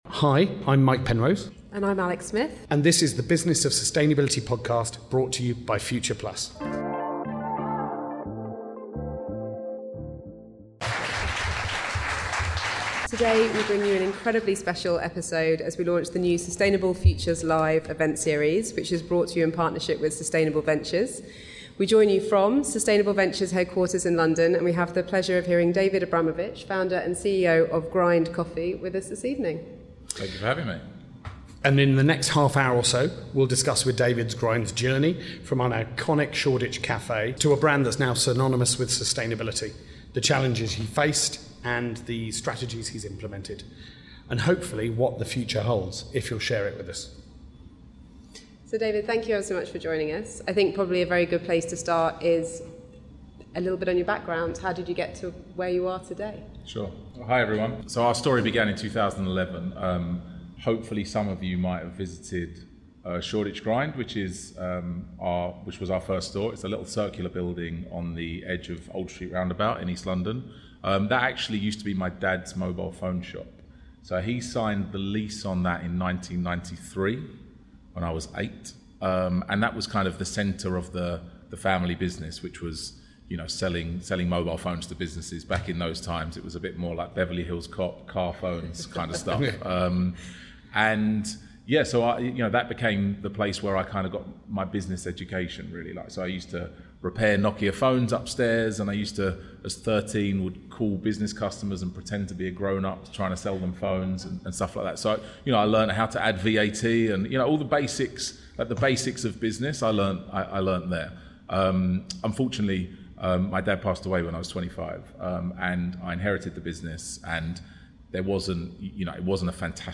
for a very special podcast recorded live at Sustainable Ventures.
This podcast was recorded as part of the launch of Sustainable Futures Live, a new event series in partnership with FuturePlus and Sustainable Ventures.